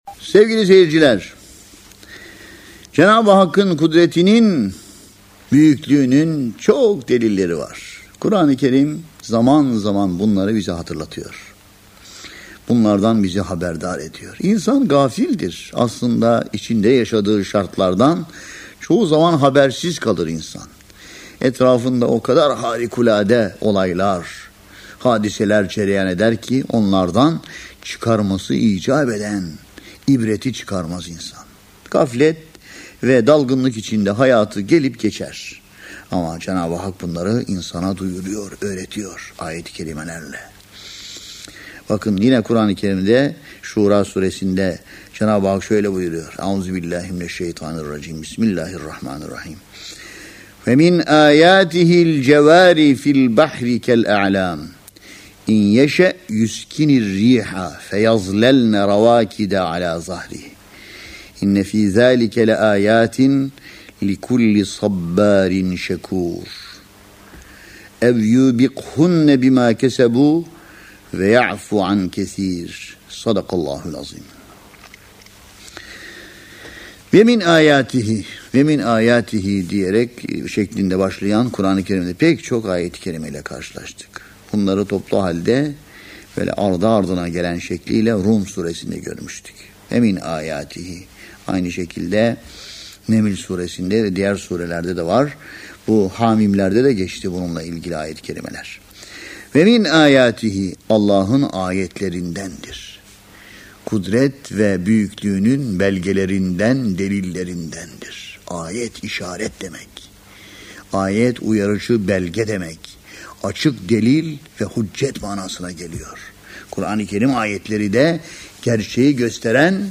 Tefsir